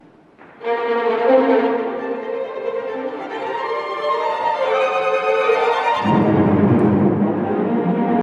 とても荒々しい曲調と、牧歌的なレントが交互にあらわれる楽章です。
2回ほど繰り返したあと、フィナーレに向かってじわじわと盛り上げます。